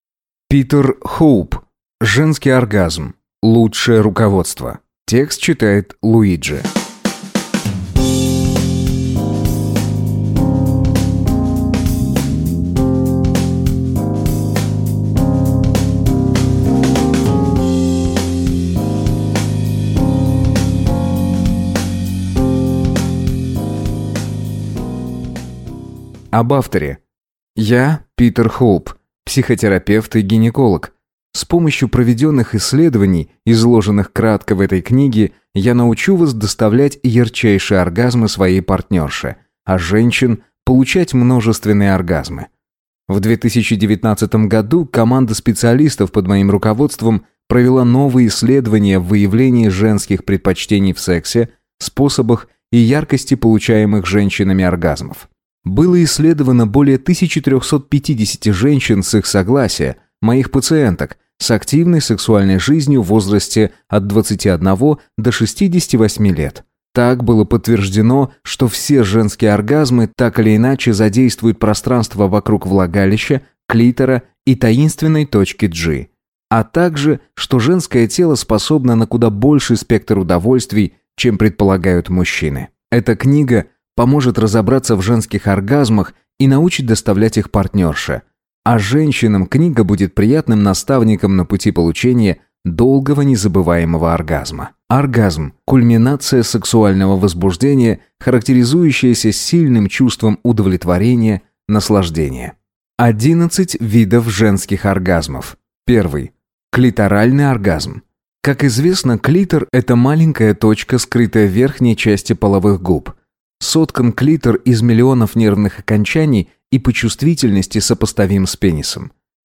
Аудиокнига Женский оргазм. Лучшее руководство | Библиотека аудиокниг